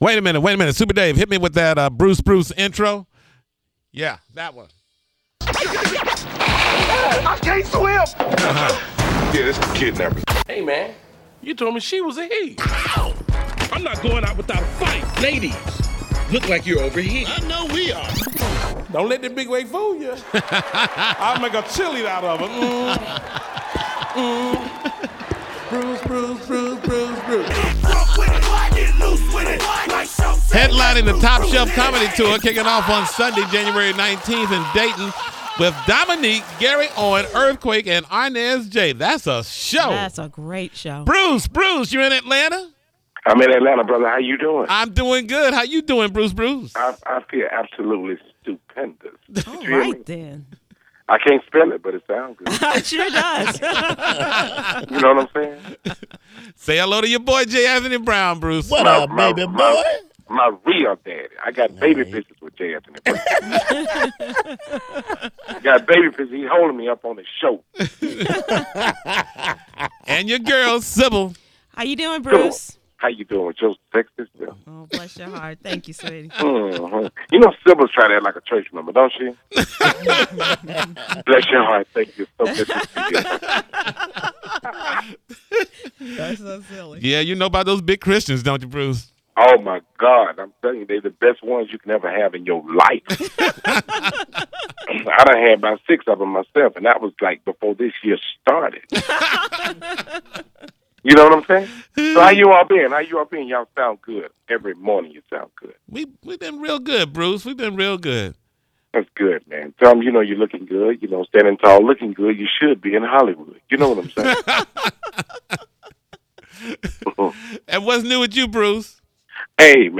01/14/14- Comedian Bruce Bruce talks with Tom Joyner Morning Show about his upcoming movie “Finally Famous”, his New Year’s celebration at Creflo Dollar’s church, and dating.